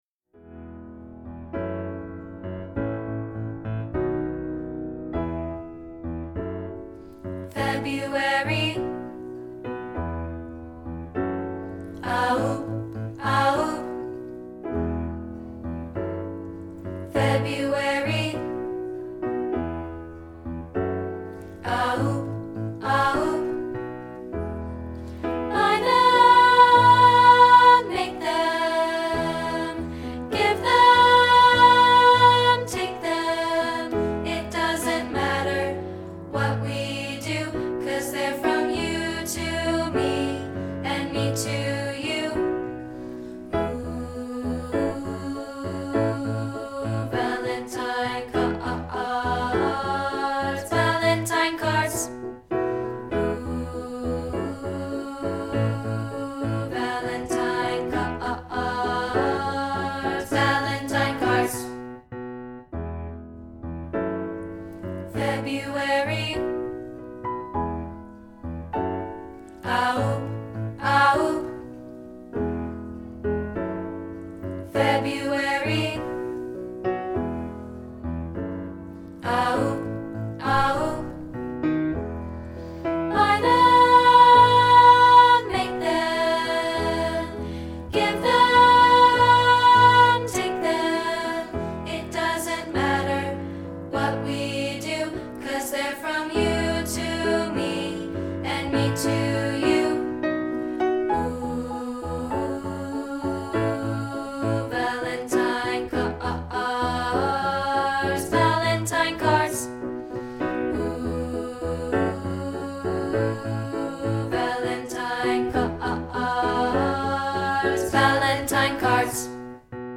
rehearsal track of part 2, isolated,